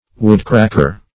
Woodcracker \Wood"crack`er\, n.